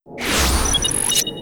medi_shield_deploy.wav